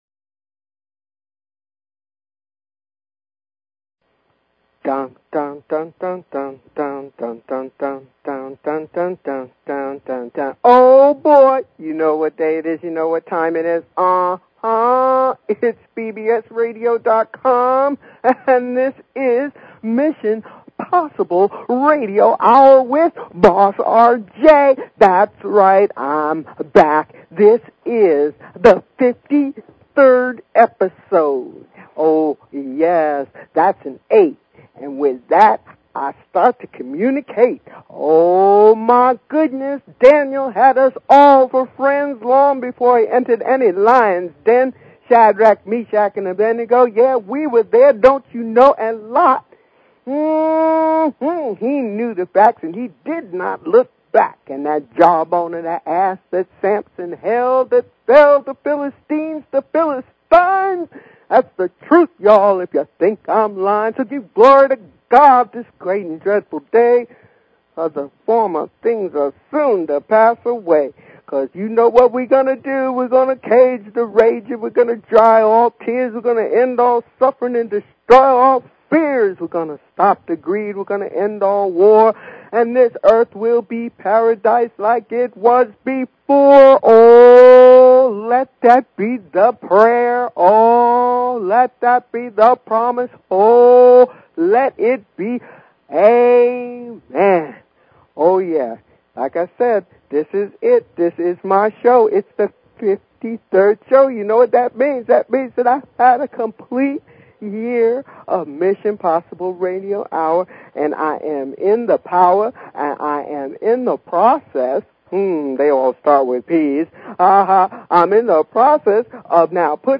Talk Show Episode, Audio Podcast, Mission_Possible and Courtesy of BBS Radio on , show guests , about , categorized as
Mission Possible Radio (MPR) is a LIVE call-in show